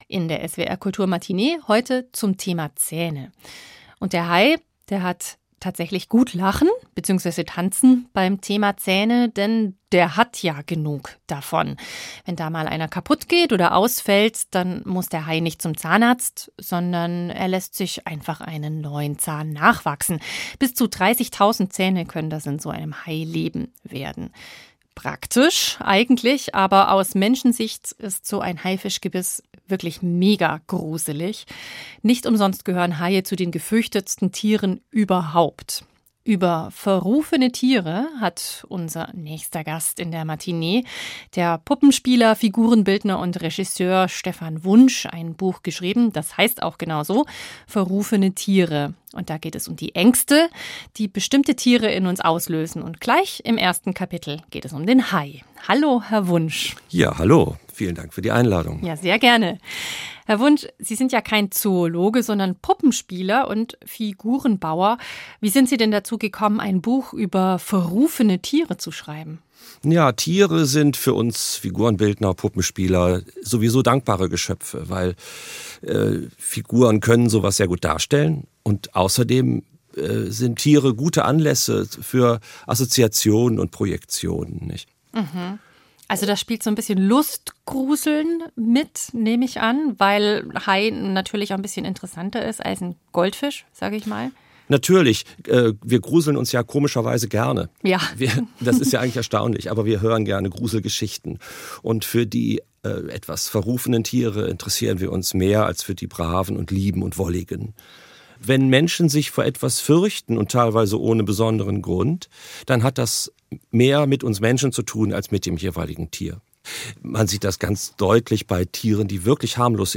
Das Interview führte